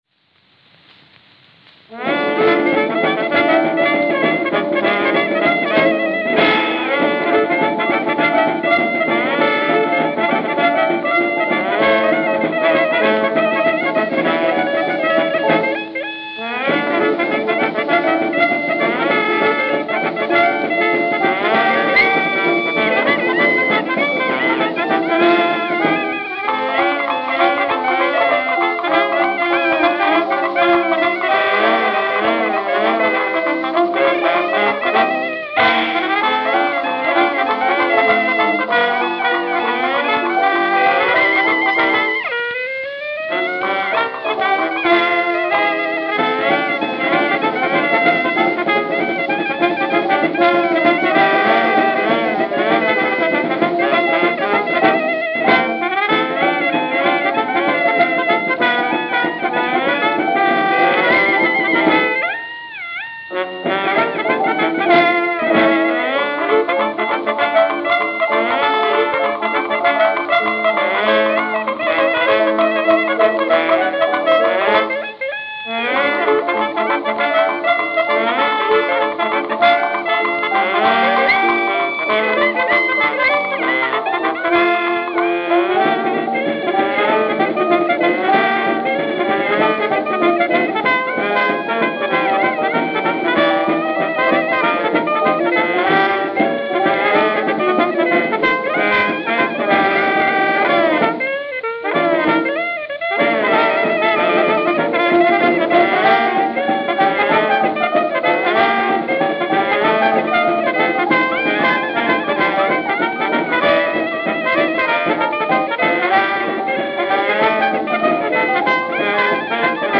clarinet & leader
piano
trombone
cornet
drums